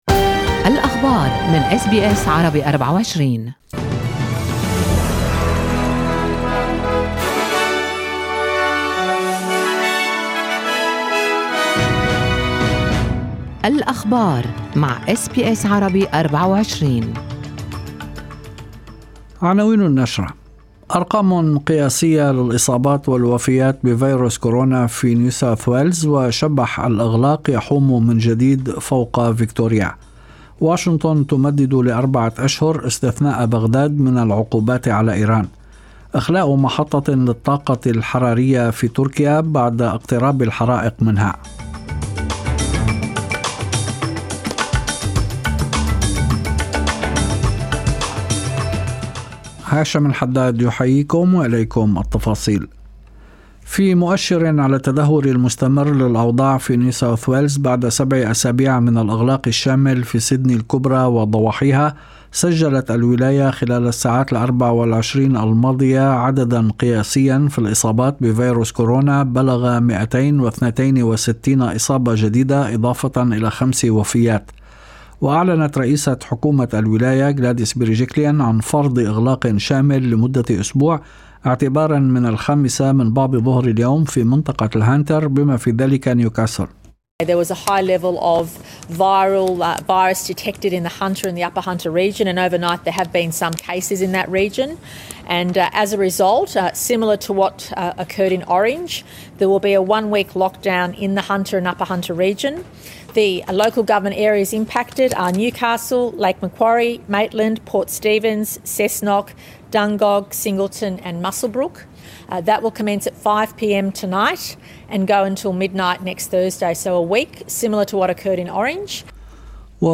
نشرة أخبار المساء 5/8/2021